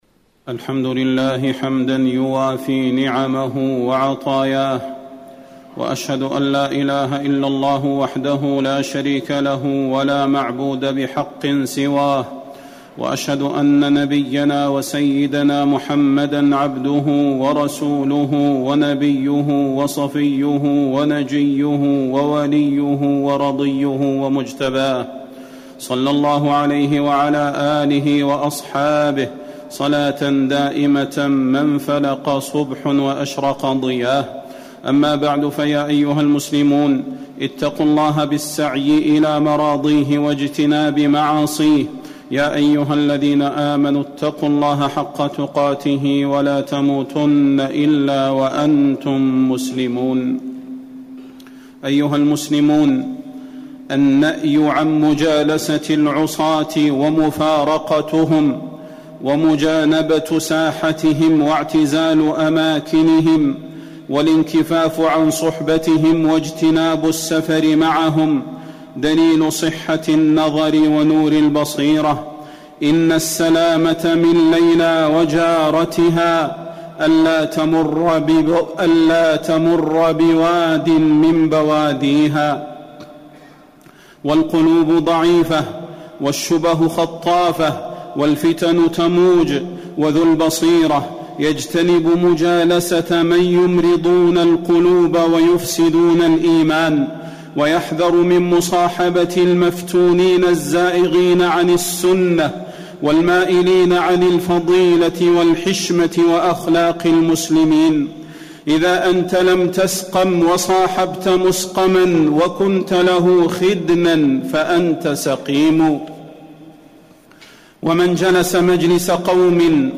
تاريخ النشر ٢٢ شوال ١٤٣٩ هـ المكان: المسجد النبوي الشيخ: فضيلة الشيخ د. صلاح بن محمد البدير فضيلة الشيخ د. صلاح بن محمد البدير النهي عن مجالسة العصاة